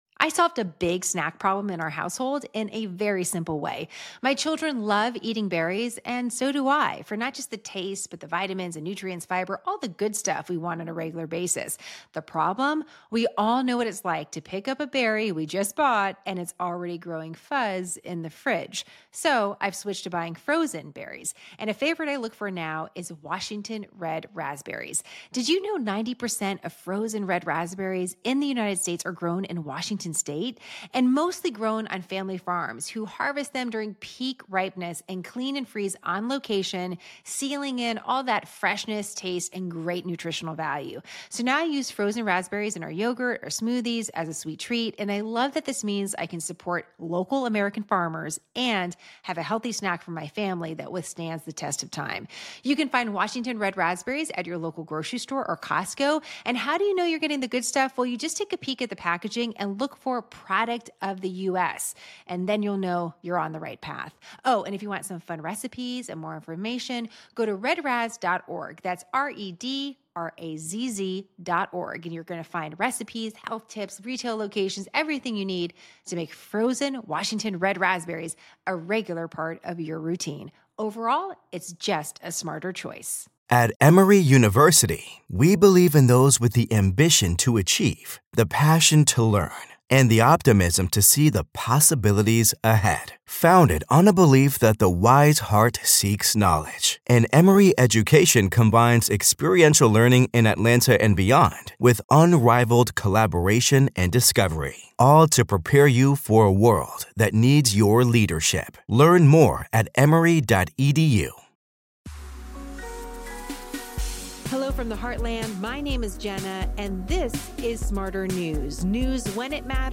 INTERVIEW: Biting The Bullet - Yes, American Soldiers Really Did This ...